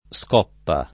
[ S k 0 ppa ]